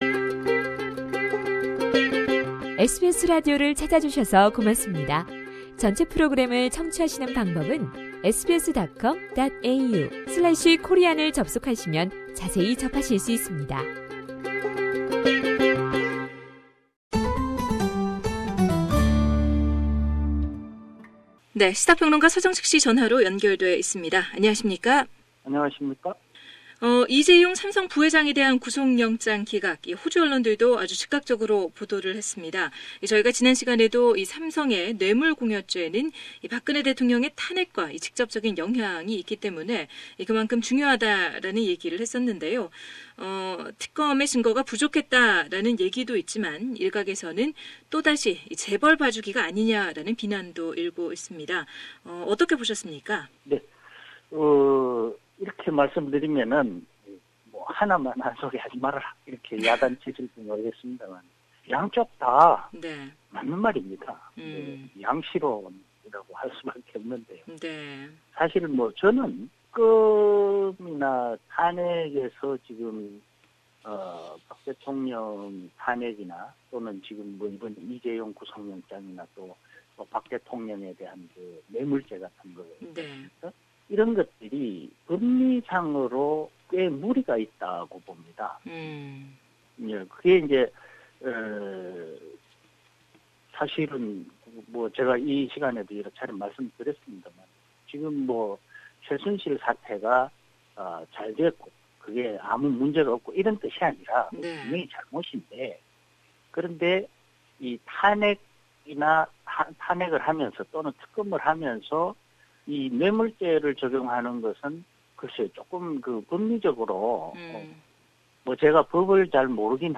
Political commentator